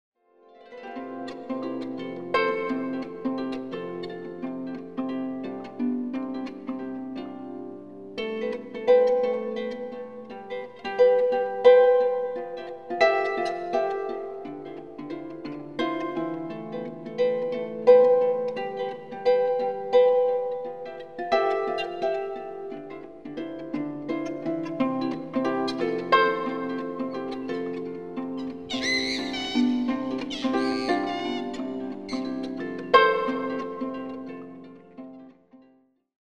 Featuring the harp, piano & guitar
Recorded at Healesville Sanctuary